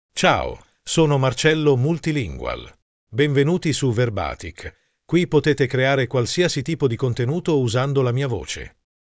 Marcello MultilingualMale Italian AI voice
Marcello Multilingual is a male AI voice for Italian (Italy).
Voice sample
Listen to Marcello Multilingual's male Italian voice.
Marcello Multilingual delivers clear pronunciation with authentic Italy Italian intonation, making your content sound professionally produced.